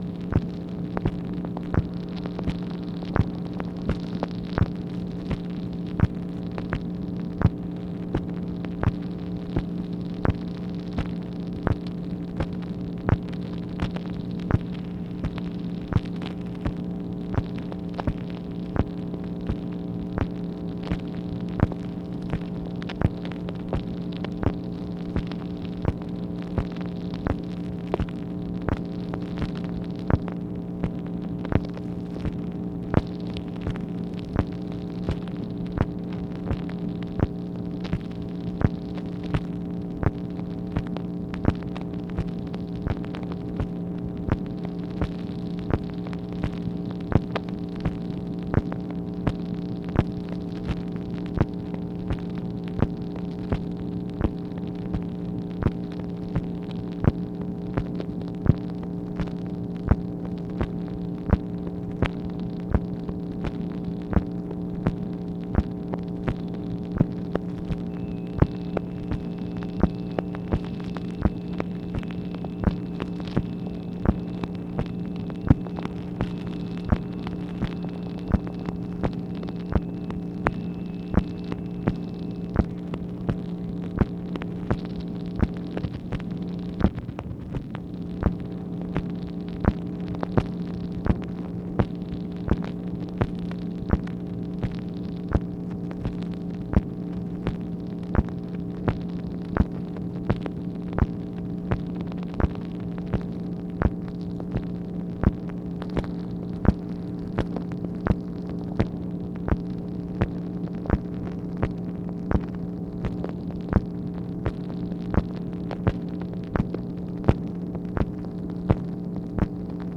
MACHINE NOISE, May 12, 1965
Secret White House Tapes | Lyndon B. Johnson Presidency